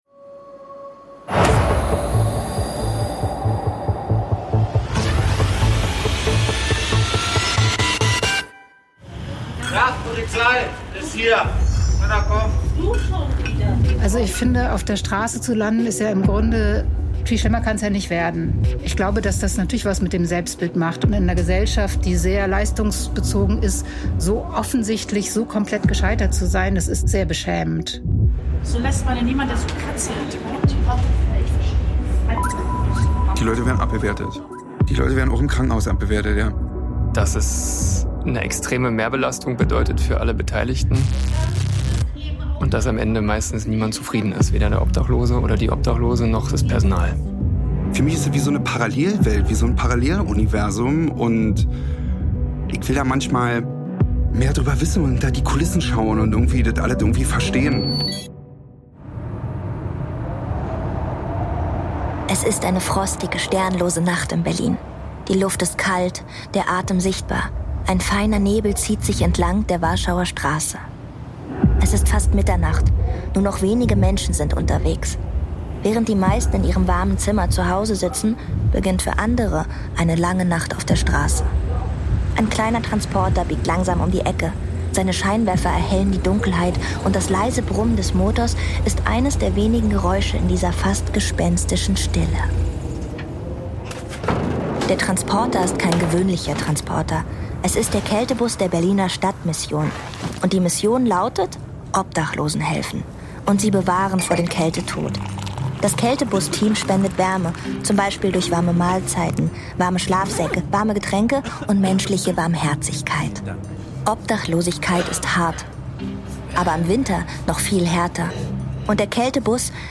Station: Auf den Straßen Berlins zwischen Rettungsstelle im Vivantes Klinikum im Friedrichshain und der Berliner Stadtmission